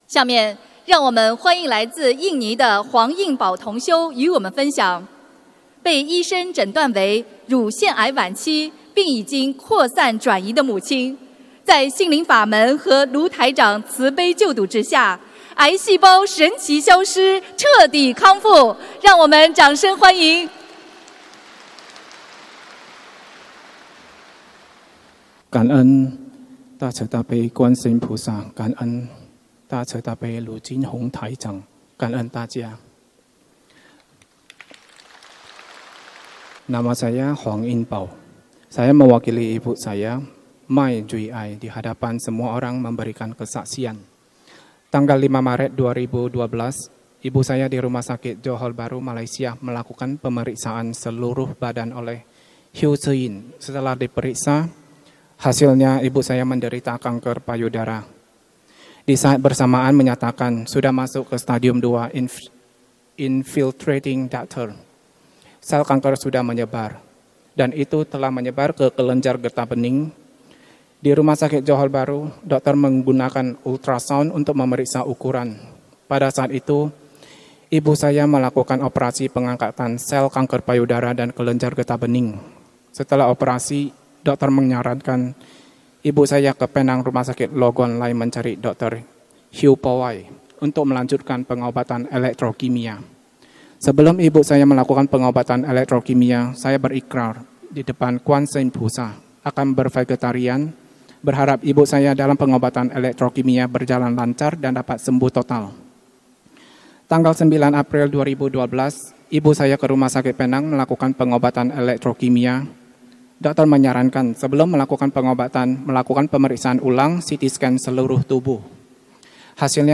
音频：三大法宝让身患乳腺癌的同修母亲彻底康复（印尼语）--2017年4月23日 印尼·雅加达法会【同修发言】